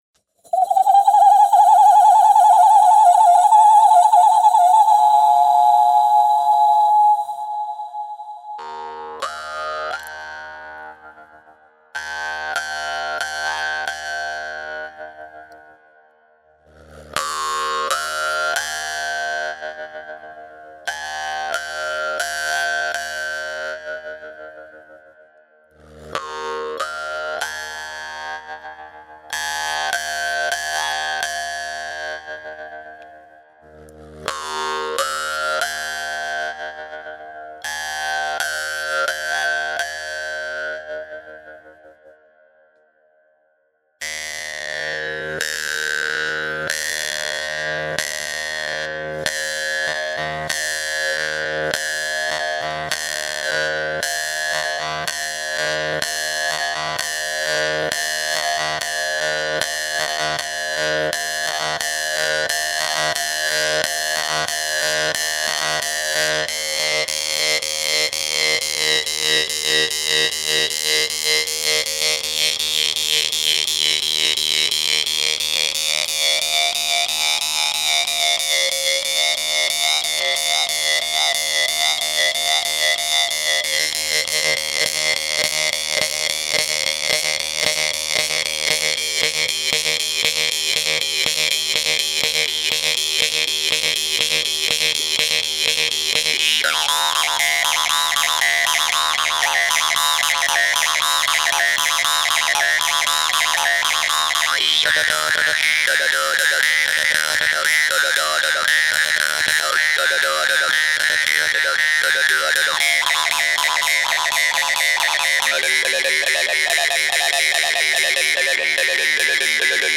Live Jew's-Harp
Genre: Electronic.